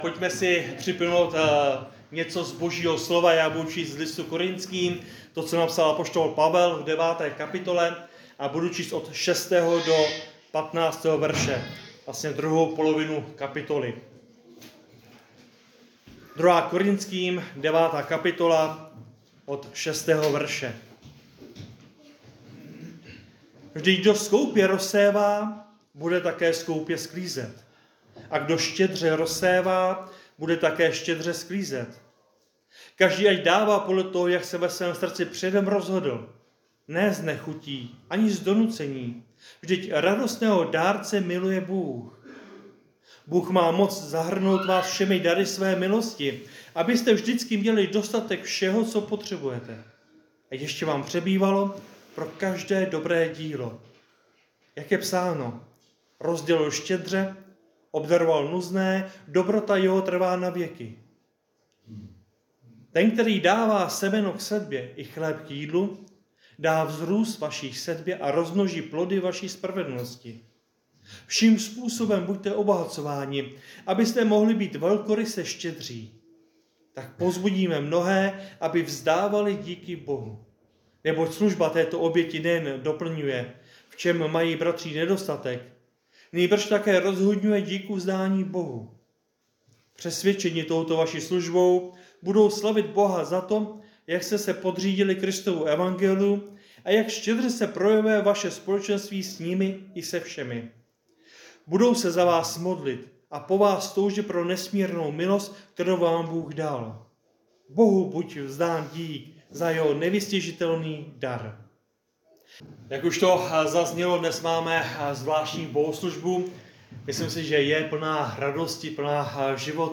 Kategorie: Kázání